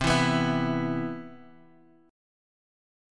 E5/C chord
E-5th-C-8,7,9,9,x,x.m4a